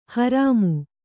♪ harāmu